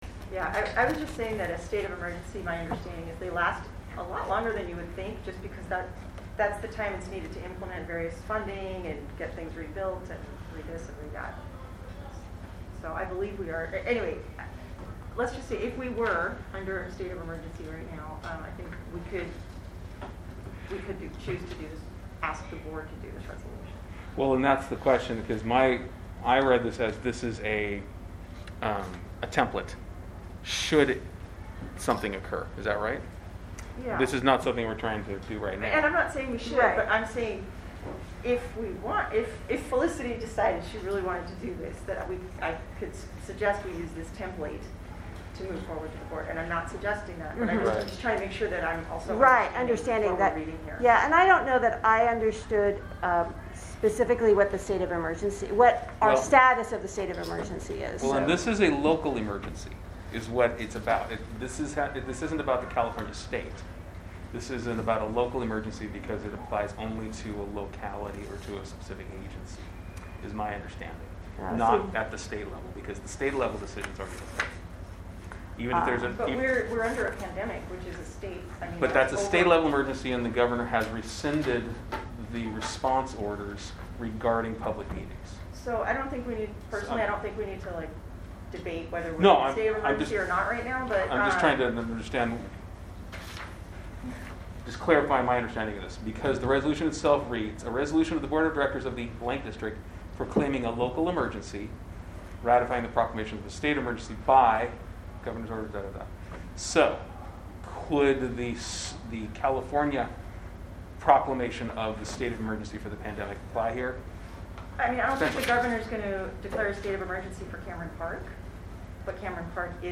Fire and Emergency Services Committee Meeting